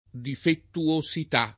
difettoso [difett1So] agg. — antiq. difettuoso [difettu-1So] e, più raro, defettuoso [defettu-1So] — sim. l’astratto difettosità [difettoSit#+] (antiq. difettuosità [